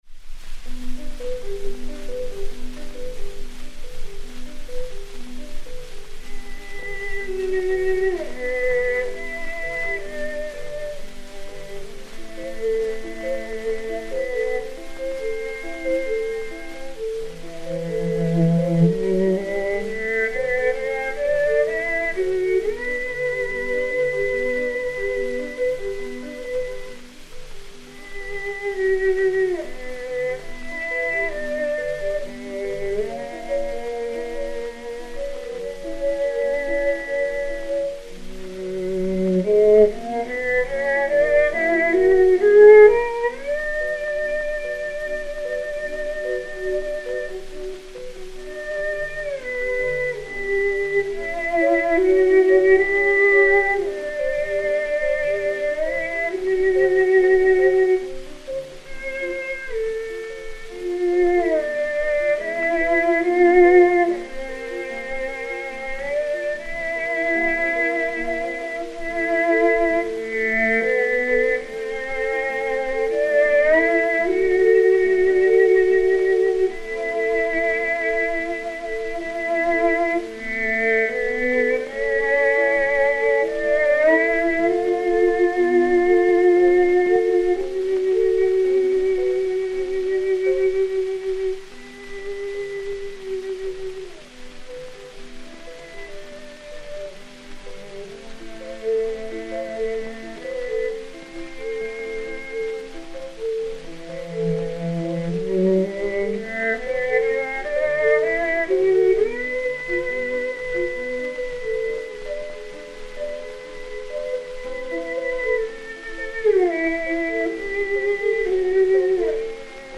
Joseph Hollman (violoncelle) et Piano
Pathé saphir 80 tours n° 9509, mat. 8545, enr. vers 1913